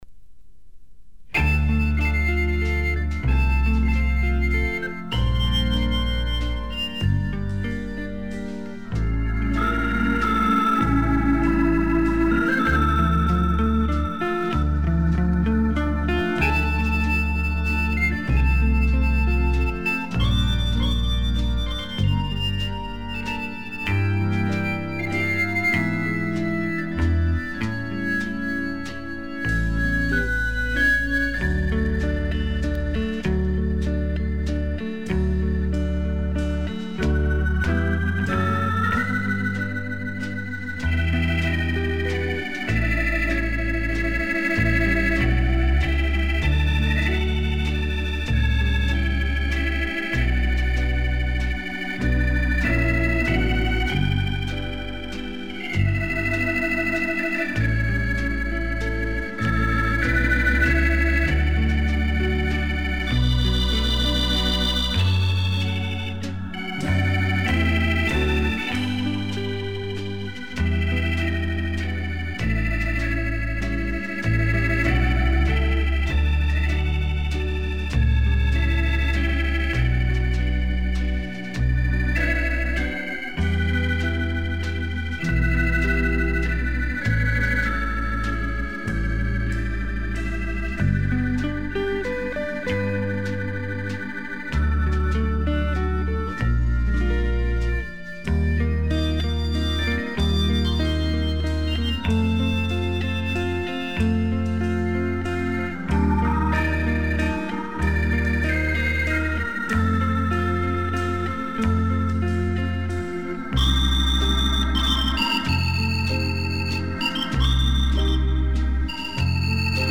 Обожаю хаммондовский орган.